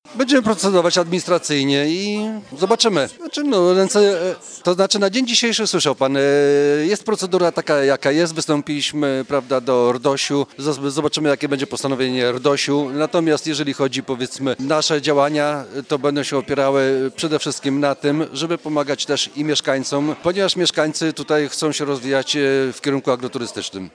– W sprawie budowy fermy zwróciliśmy się o opinię do Regionalnej Dyrekcji Ochrony Środowiska i Sanepidu, mówi Bohdan Mohyła, wójt Gminy Pozezdrze.